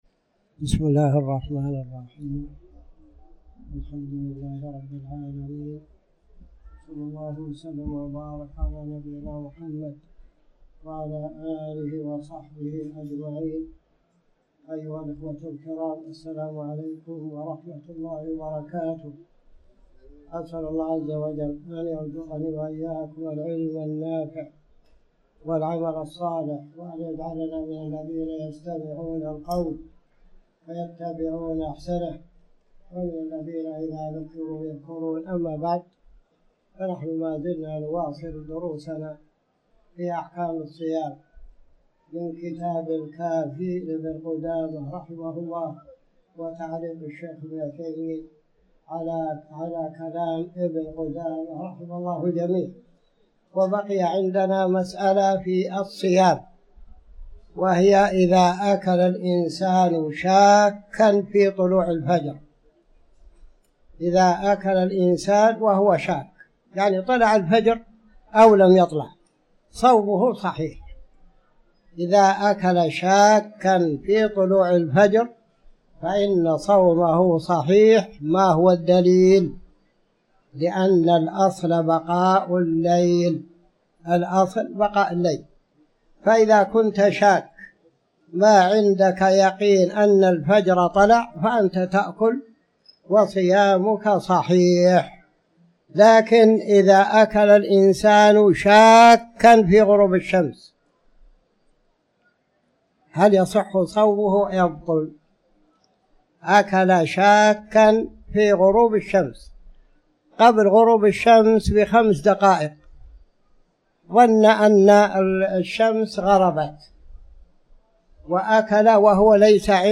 تاريخ النشر ٨ رمضان ١٤٤٠ هـ المكان: المسجد الحرام الشيخ